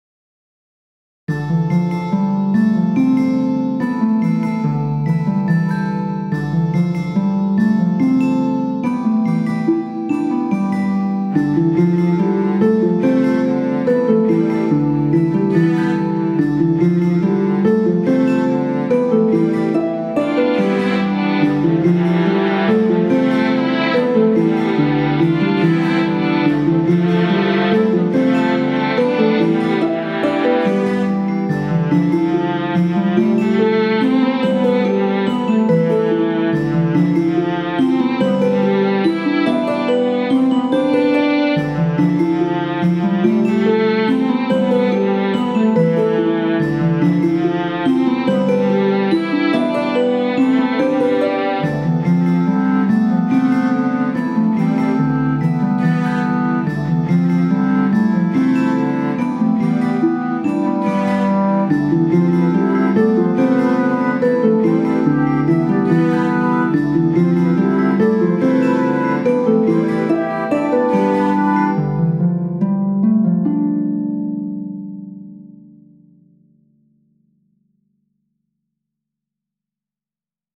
BGM
アップテンポ明るい